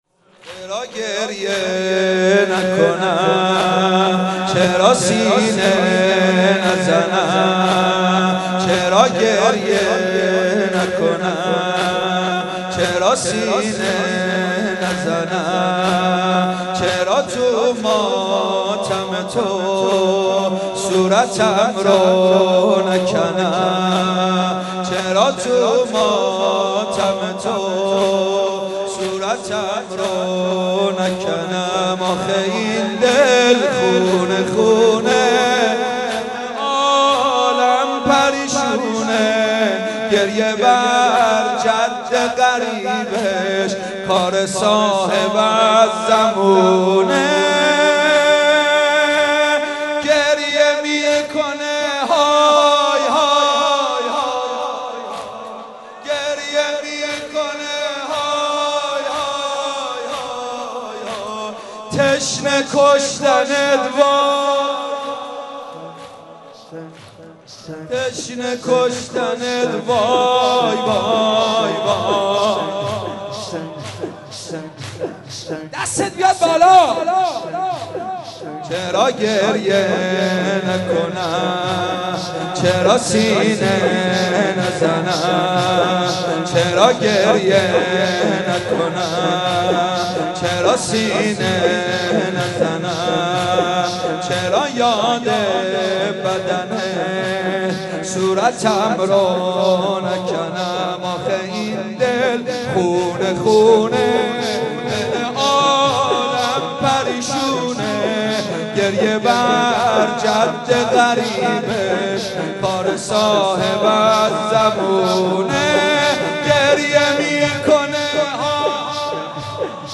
مناسبت : شب سی ام رمضان
قالب : زمینه